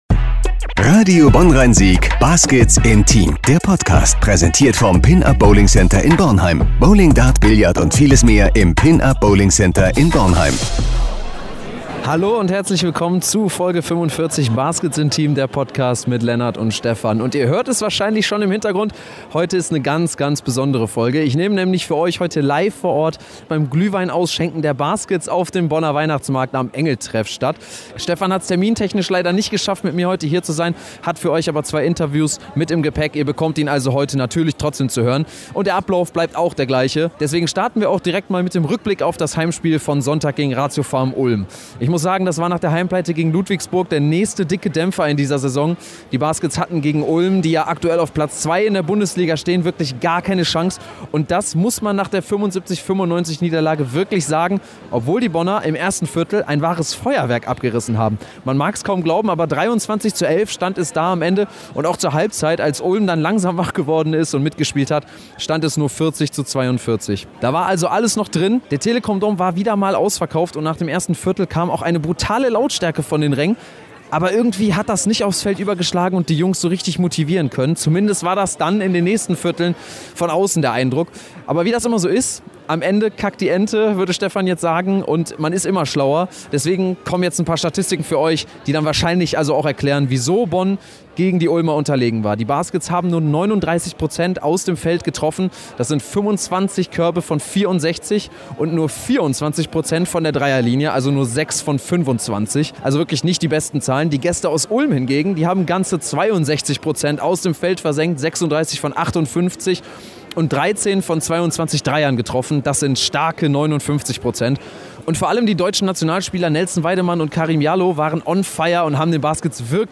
Und natürlich jede Menge Fans!